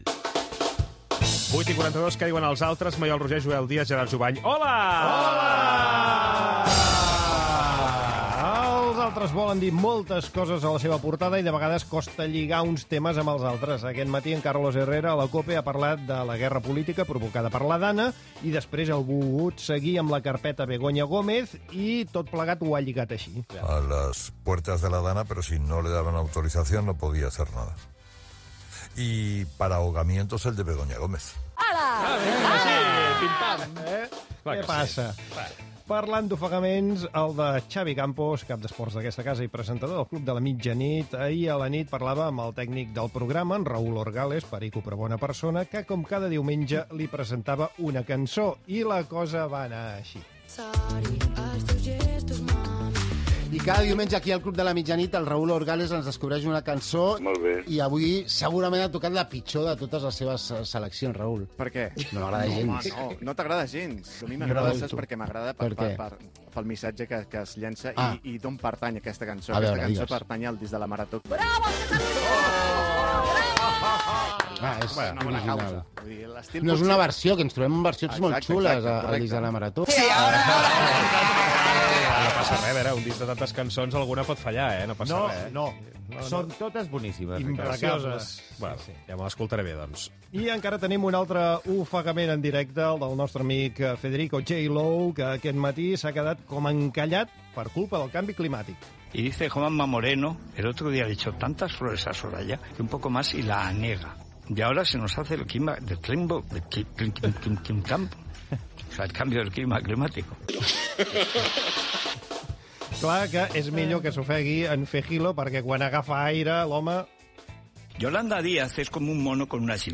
Què diuen els altres? Amb un recull de fragments radiofònics, la gestió de la DANA al País Valencià, el que es diu a les xarxes socials
Info-entreteniment